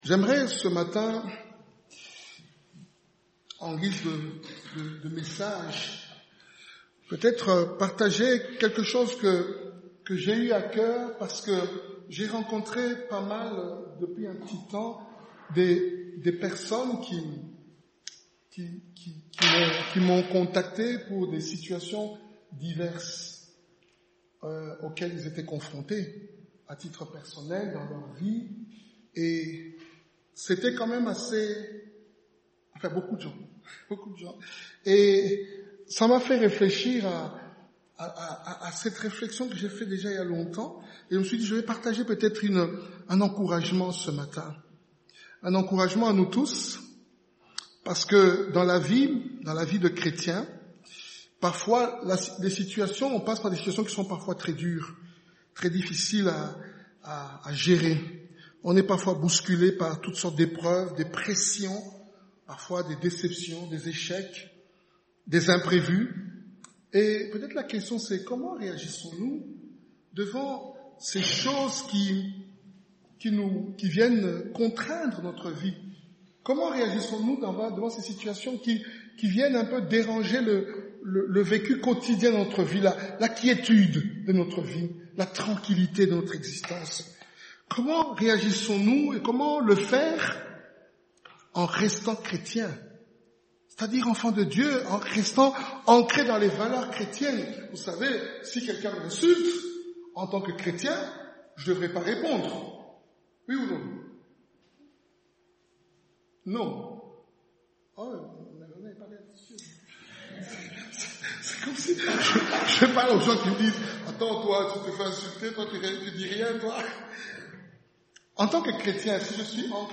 Passage: 1 Samuel 30 Type De Service: Dimanche matin